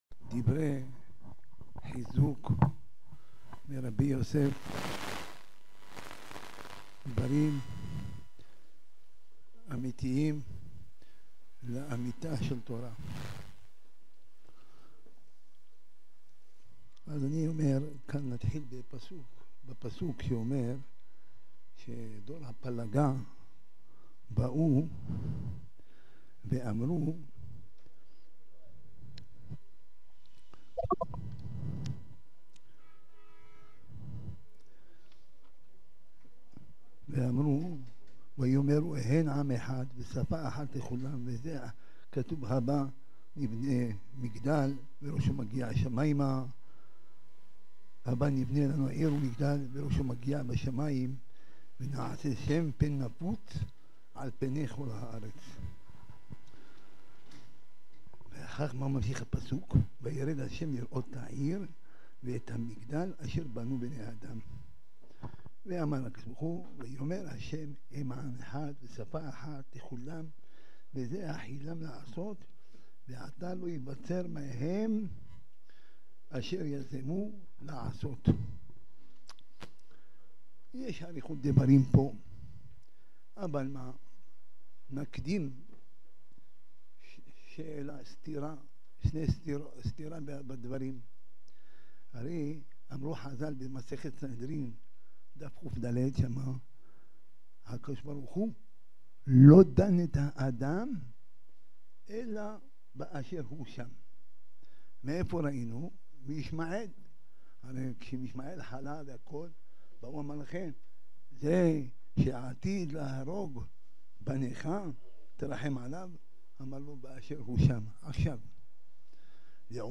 נאום כבוד הדיין הרב רפאל רוזיליו - זקן רבני מרוקו